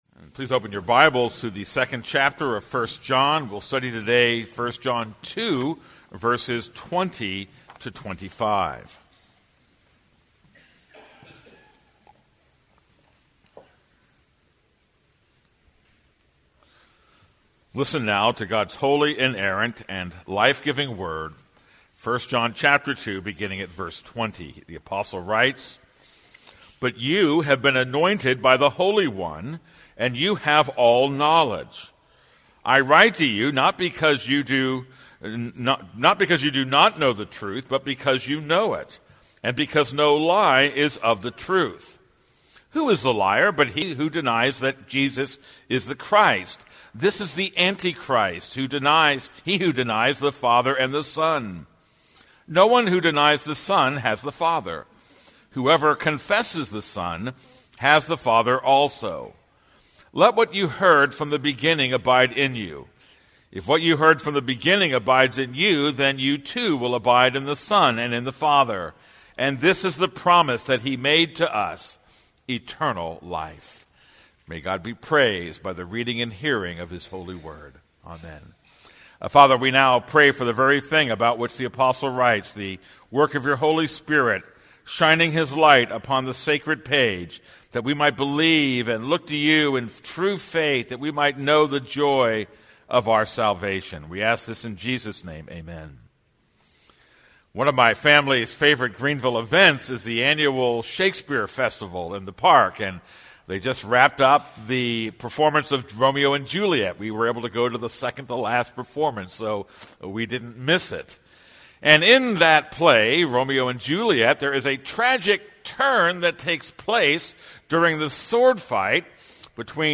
This is a sermon on 1 John 2:20-25.